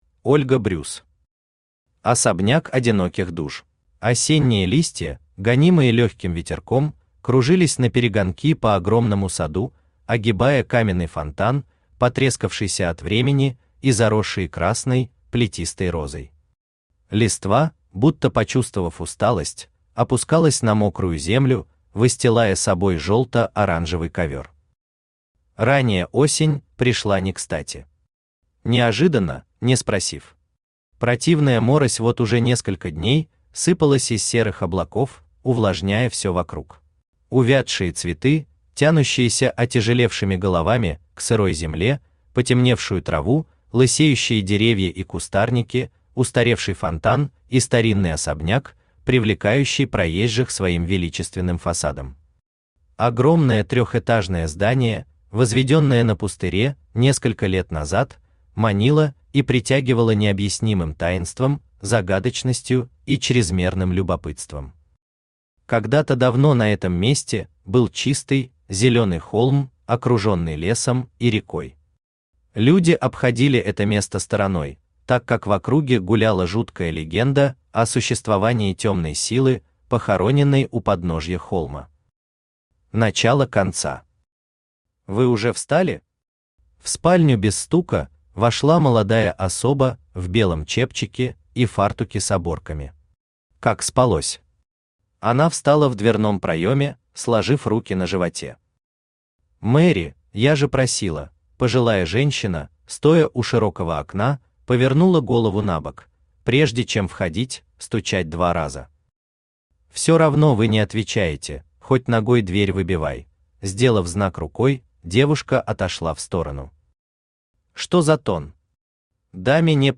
Аудиокнига Особняк одиноких душ | Библиотека аудиокниг
Aудиокнига Особняк одиноких душ Автор Ольга Брюс Читает аудиокнигу Авточтец ЛитРес.